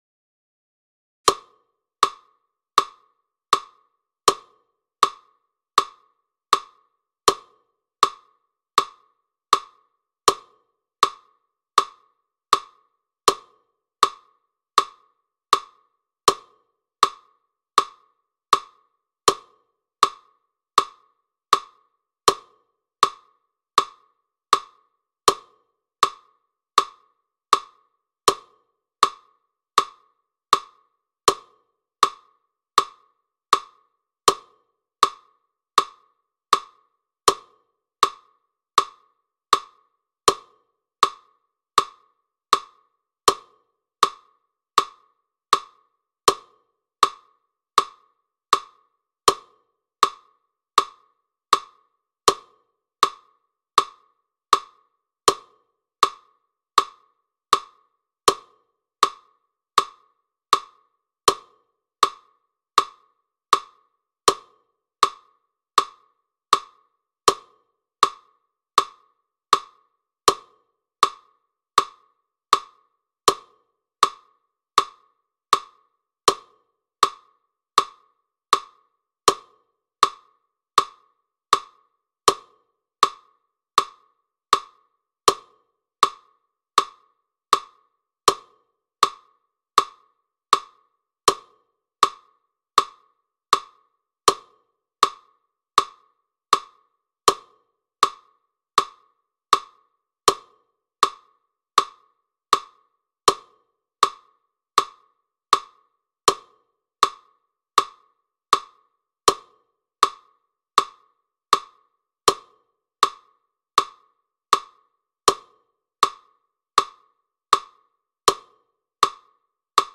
80-bpm-44-wood-metronome-hd.mp3